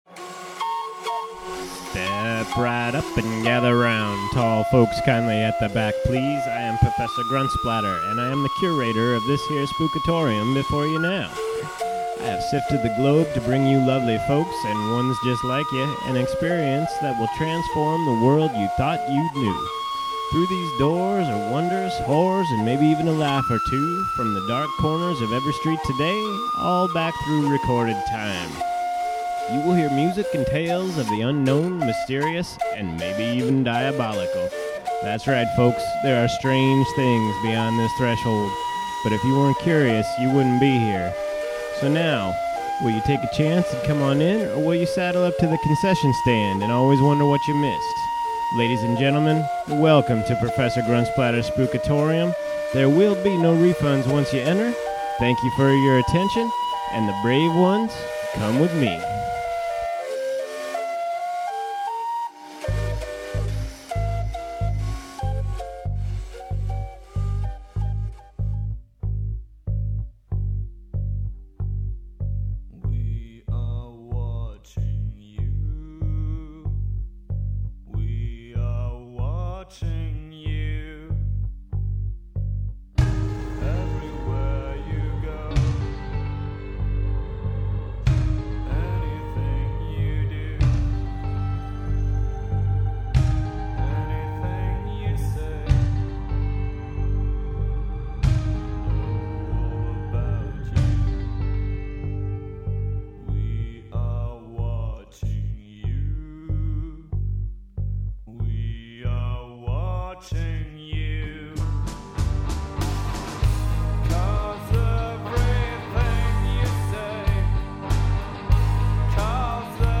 Greetings onlookers, a new episode is up featuring the delicate melodies of the following…
With background accompaniment from DEVA-LOKA NOVATRON ATOM INFANT INCUBATOR